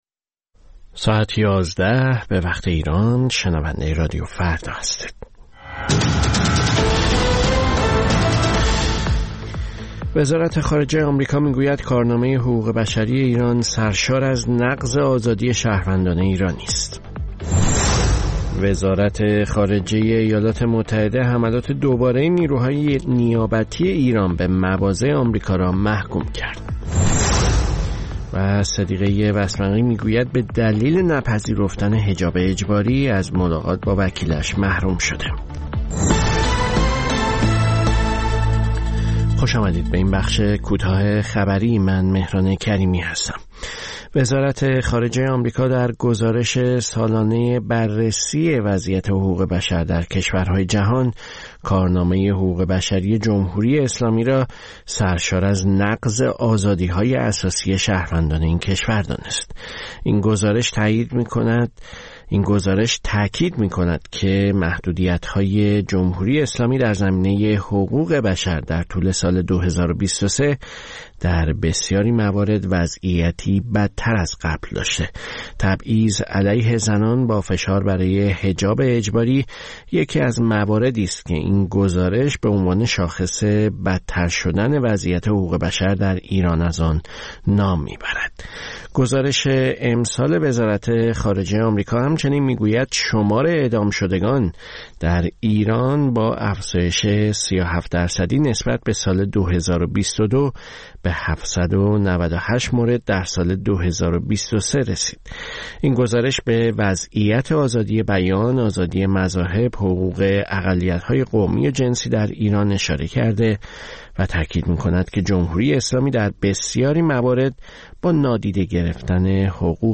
سرخط خبرها ۱۱:۰۰